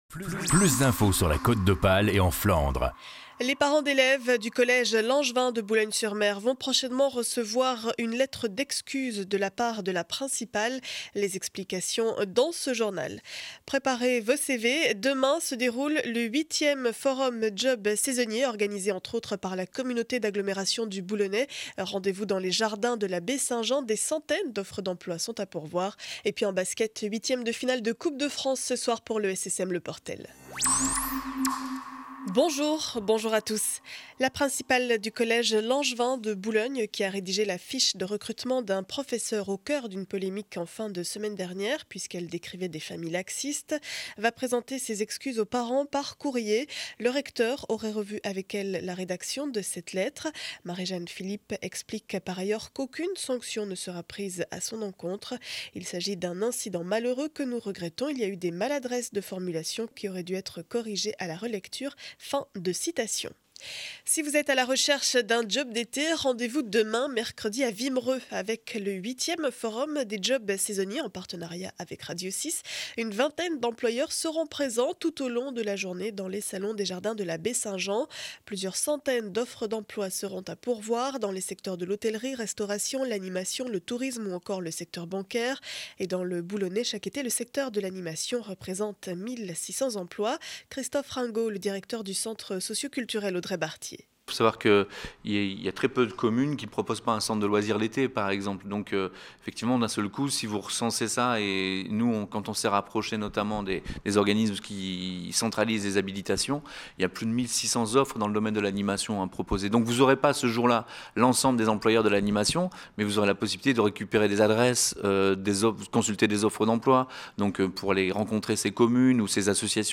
Journal du mardi 13 mars 2012 7 heures 30 édition du Boulonnais.